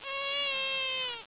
heul2.wav